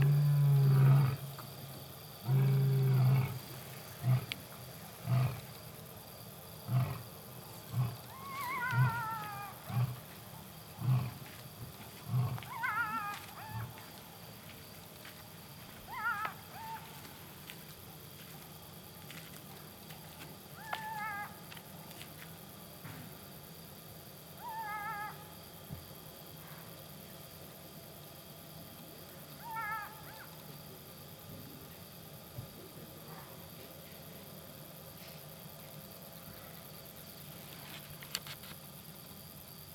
Listen how jackals join the lion roar.
jackal_quicktime.m4a